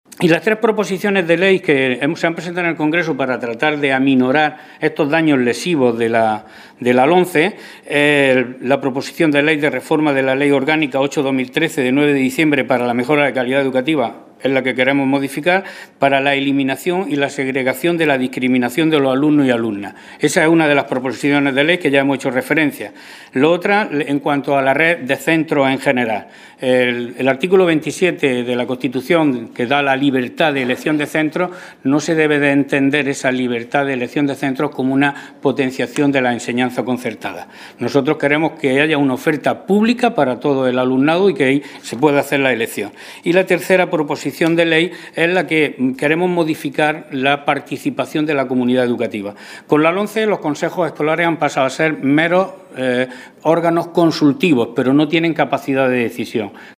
Rueda de prensa sobre educación que han ofrecido la diputada autonómica Adela Segura y el diputado nacional Juan Jiménez